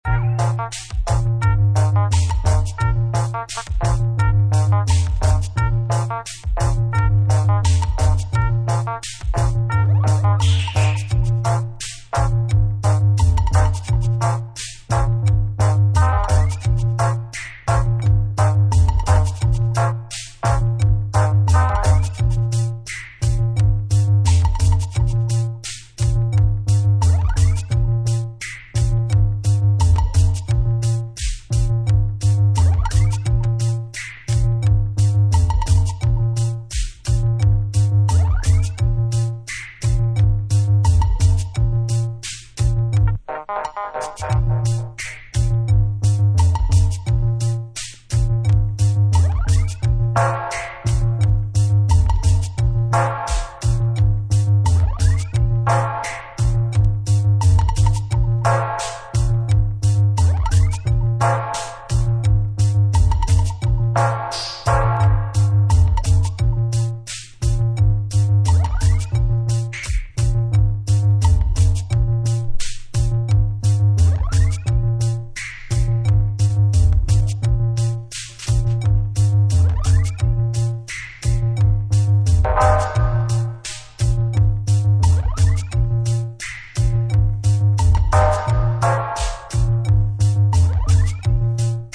80's dance hall